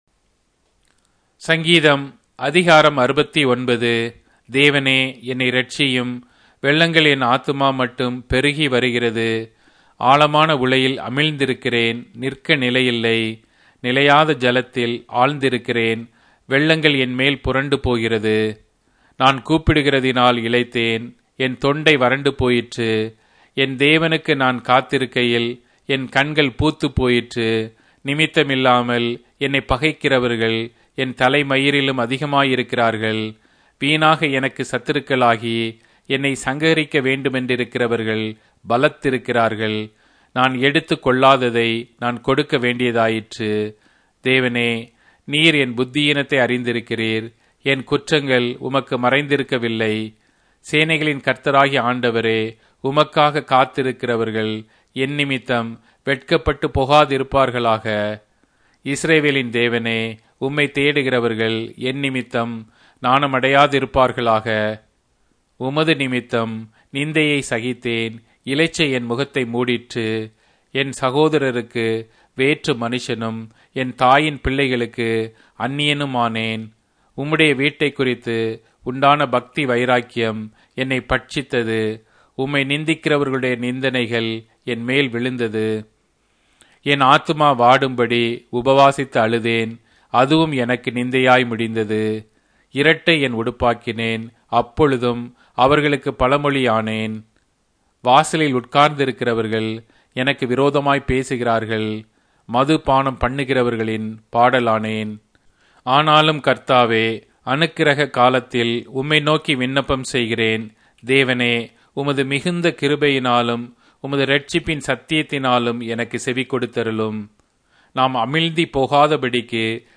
Tamil Audio Bible - Psalms 8 in Mkjv bible version